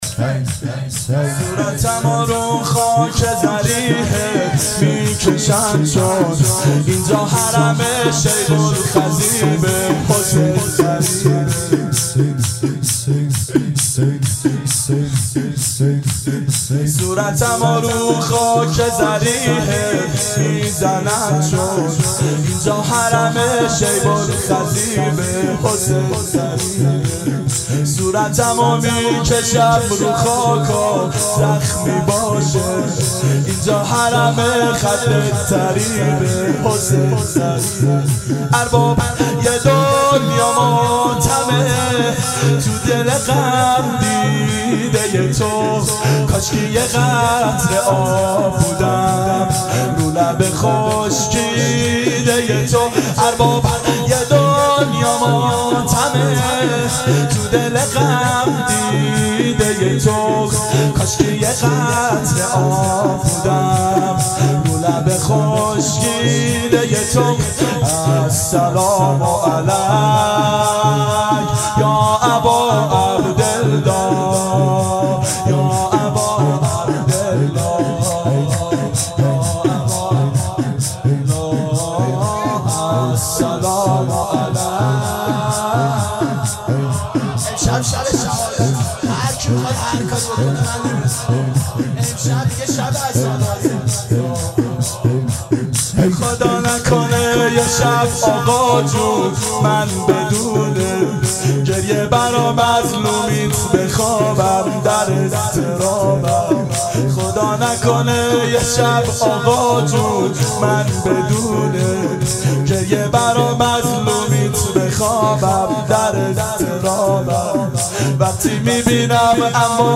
• دهه اول صفر سال 1390 هیئت شیفتگان حضرت رقیه س شب دوم (شب شهادت)